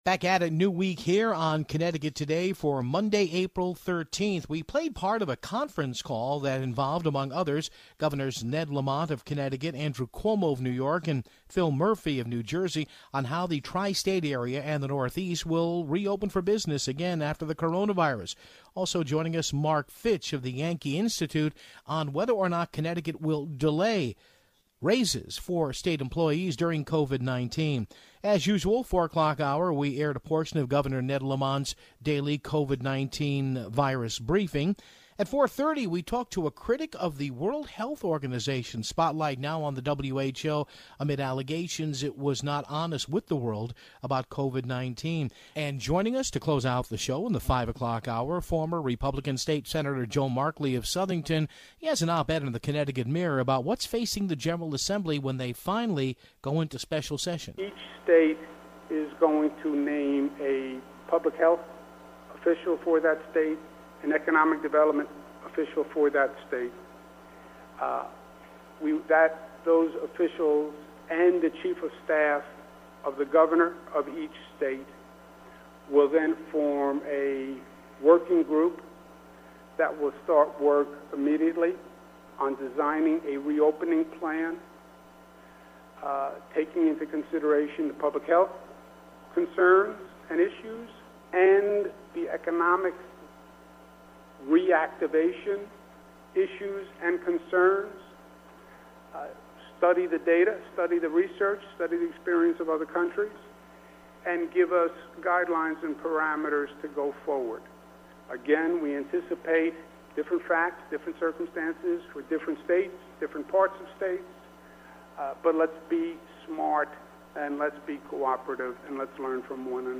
Taking priority on today's show is a conference call between several area governors, including Cuomo and Lamont.